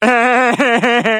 Sound Effects
Peter Griffin Laugh